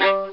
Fiddle Short(g) Sound Effect
fiddle-short-g.mp3